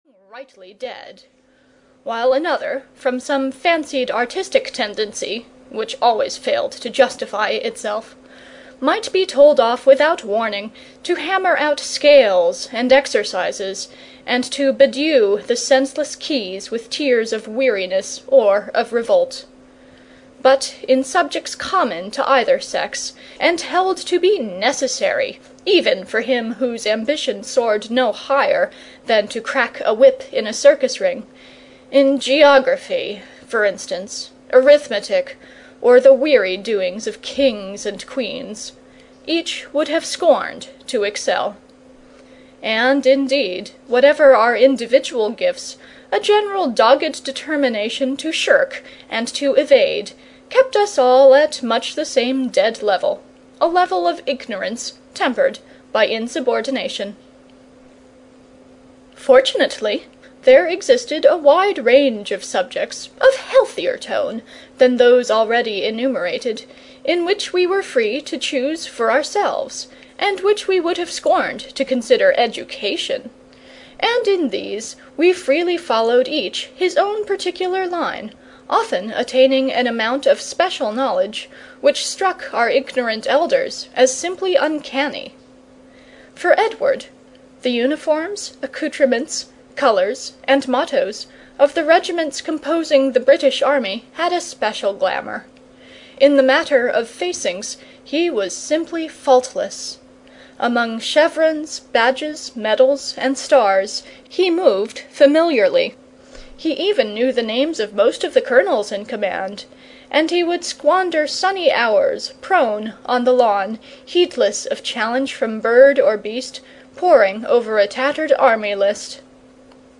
Dream Days (EN) audiokniha
Ukázka z knihy